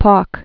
(pôk, pôlk)